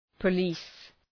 Προφορά
{pə’li:s}